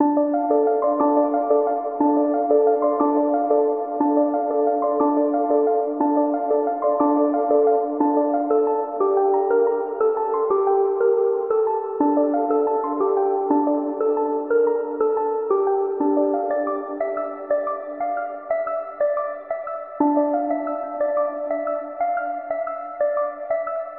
标签： 120 bpm Chill Out Loops Fx Loops 4.04 MB wav Key : Unknown
声道立体声